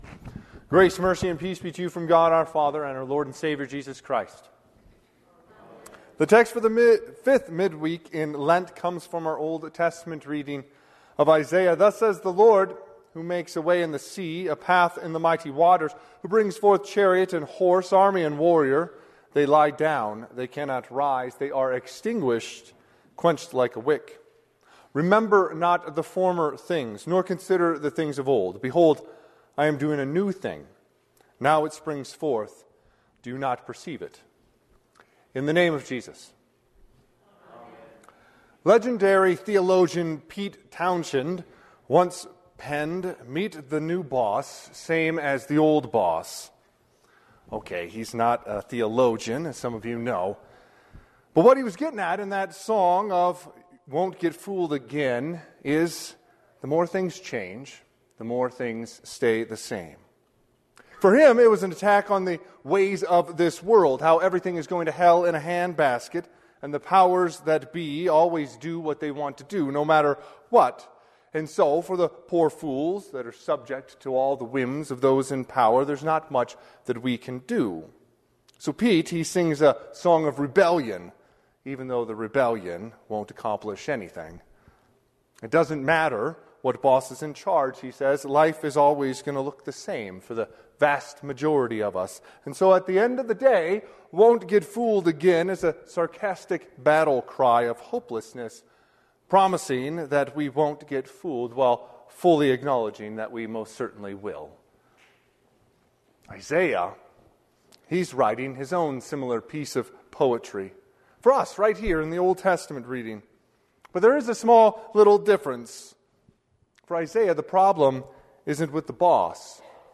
Sermon - 4/9/2025 - Wheat Ridge Lutheran Church, Wheat Ridge, Colorado
The Fifth Midweek in Lent
Sermon_Apr9_2025.mp3